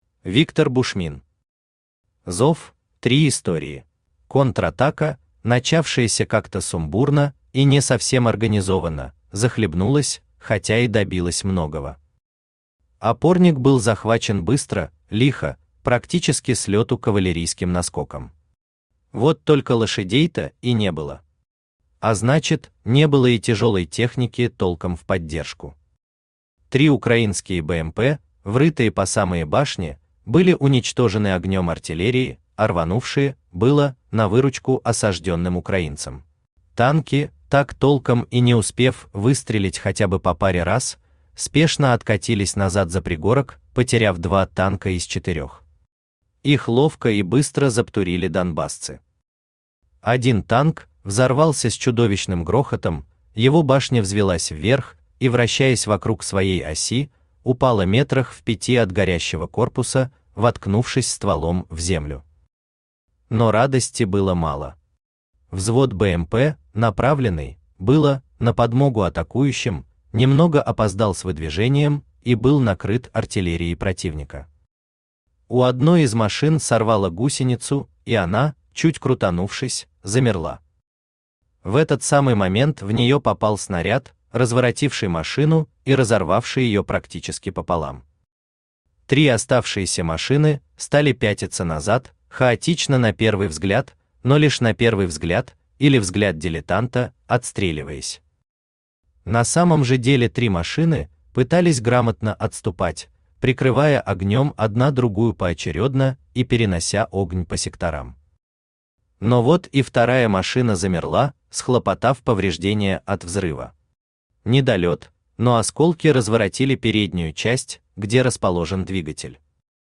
Аудиокнига ЗОВ.Три истории | Библиотека аудиокниг
Aудиокнига ЗОВ.Три истории Автор Виктор Васильевич Бушмин Читает аудиокнигу Авточтец ЛитРес.